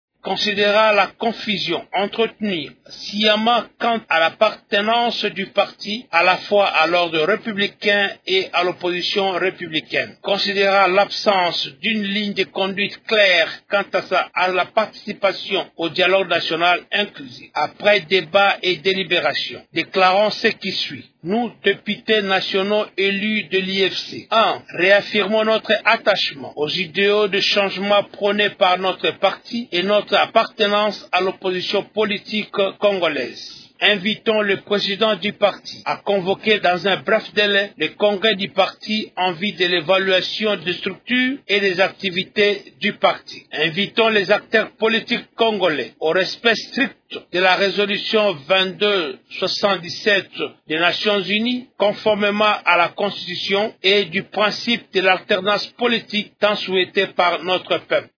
Dans leur déclaration lue par l’un d’eux, Norbert Eholo Eoni, ces députés ont exigé la tenue dans un bref délai du congrès du parti pour évaluer les structures du parti et la participation de l’UFC au dialogue.